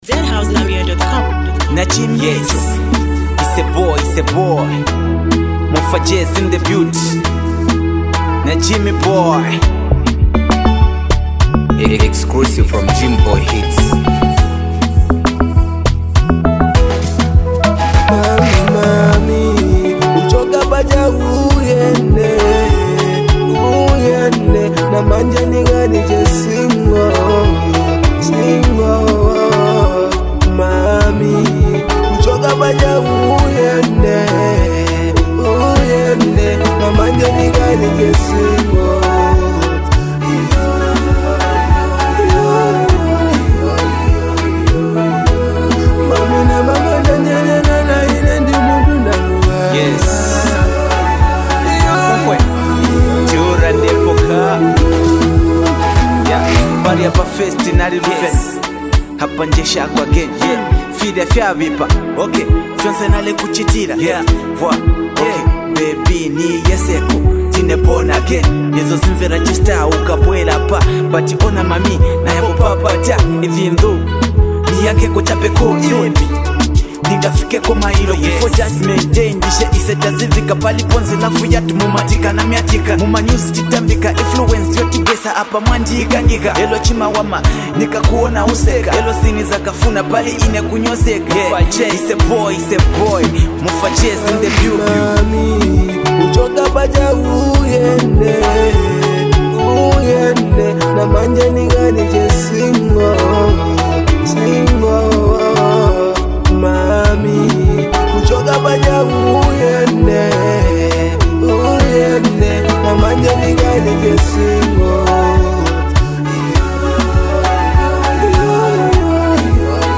smooth and emotional single